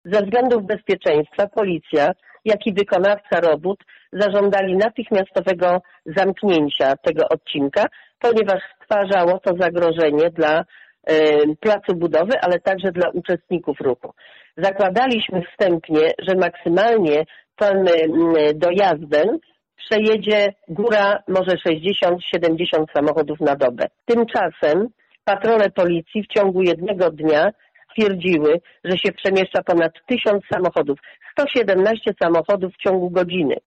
Droga została zamknięta z powodów bezpieczeństwa – wyjaśnia wójt gminy Bogdaniec, Krystyna Pławska: